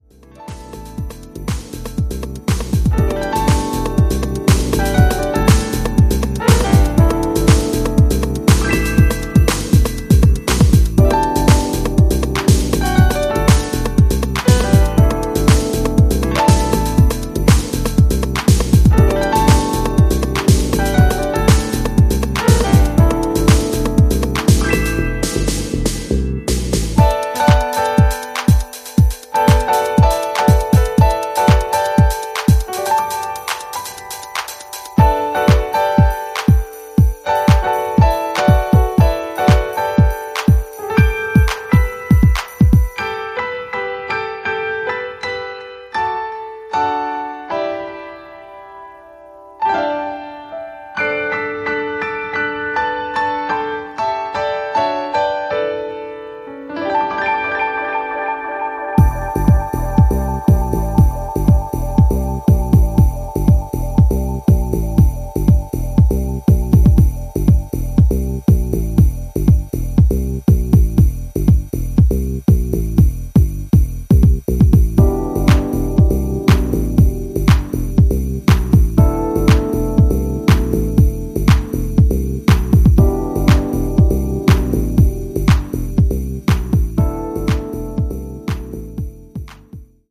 インストもおすすめ！